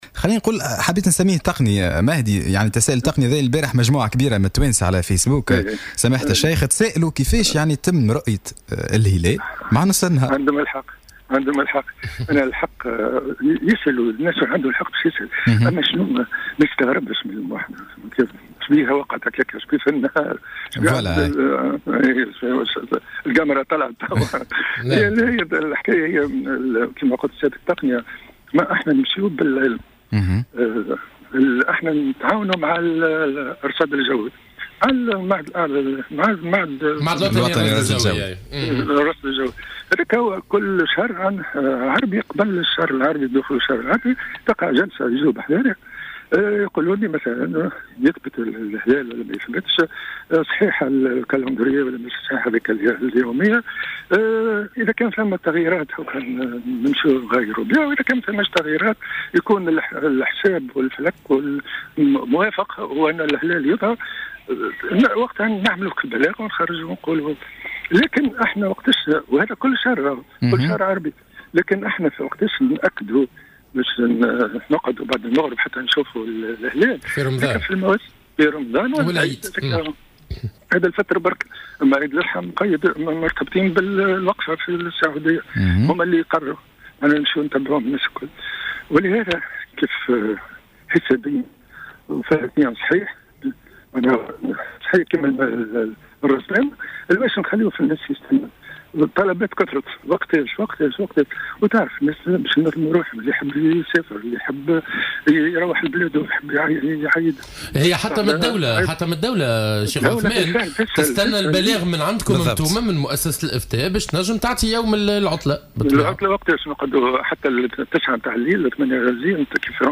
وأضاف في اتصال هاتفي ببرنامج "صباح الورد": أنه بعد التأكد من صحة الحساب الفلكي تم الاعلان عن رأس السنة الهجرية أي الأول من شهر محرم 1439 هجري الموافق لليوم الخميس 21 سبتمبر 2017 ميلادي.